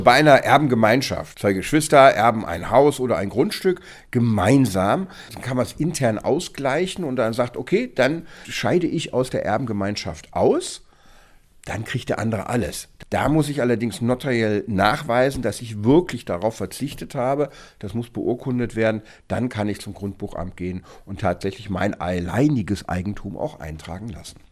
O-Ton: Ändert sich die Erbengemeinschaft, muss dies eingetragen werden – Vorabs Medienproduktion